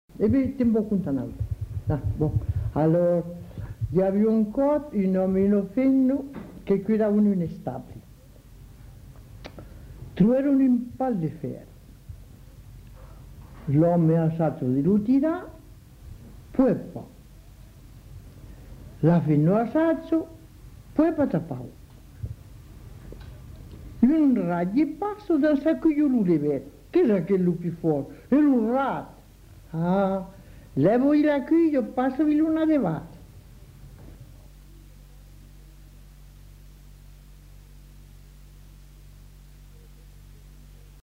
Aire culturelle : Haut-Agenais
Genre : conte-légende-récit
Effectif : 1
Type de voix : voix de femme
Production du son : récité